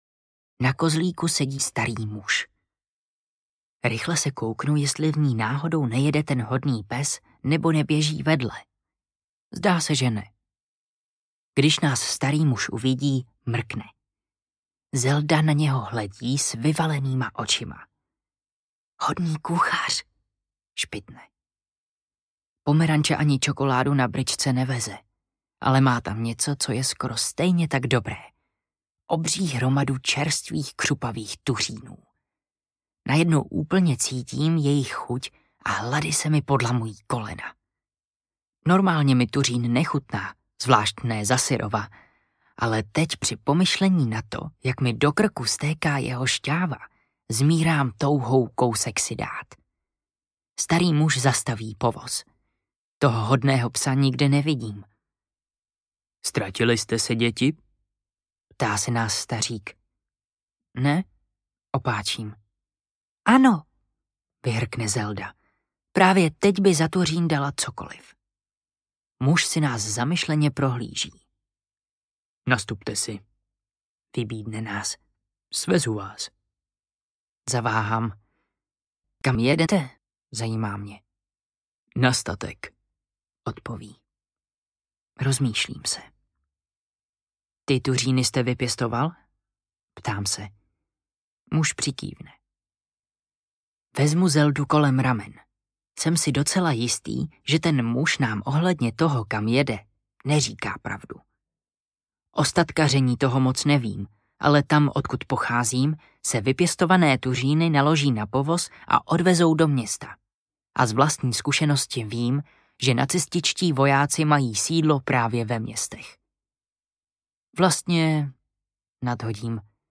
Potom audiokniha
Ukázka z knihy
Vyrobilo studio Soundguru.